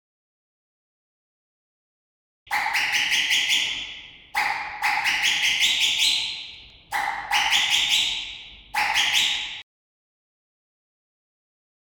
Hi Do Turkish Whistle 12V | McCallums
This is the original Turkish whistle from the old days.